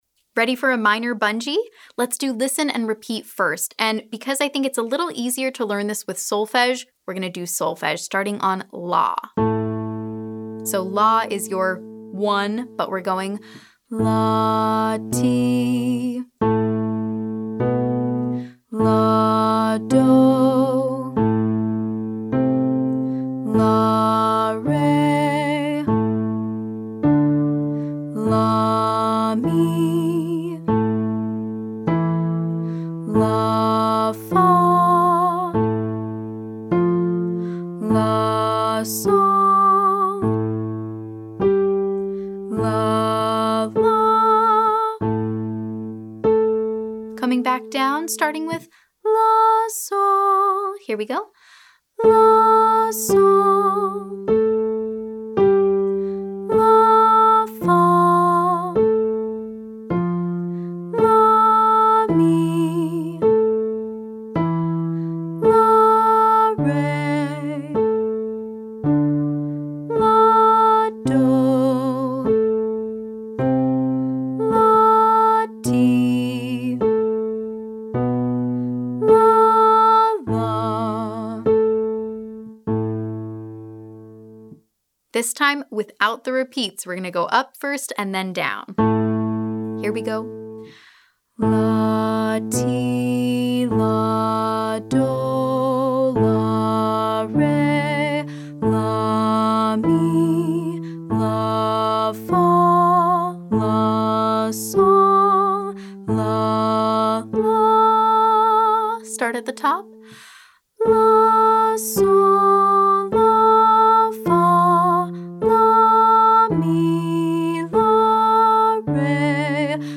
• Minor scale bungee, ascending & descending